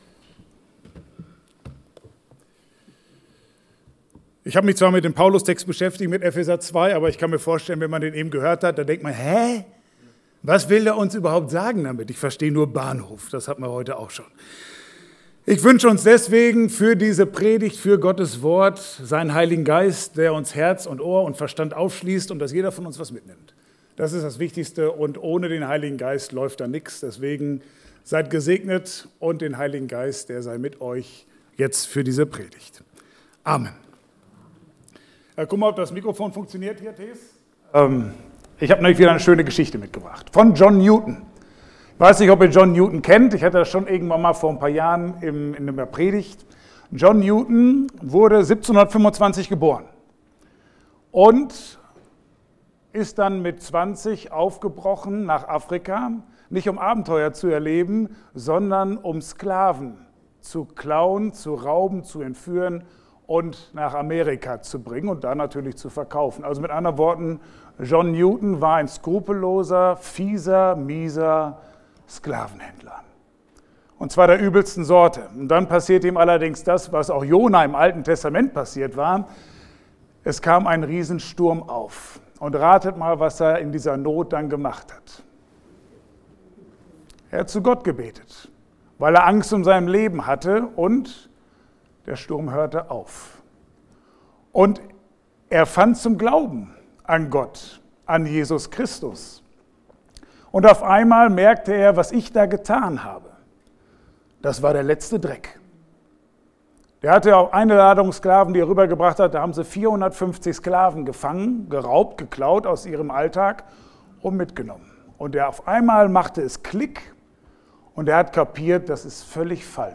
Passage: Epheser 2, 11-22 Dienstart: Gottesdienst « Gottes Wort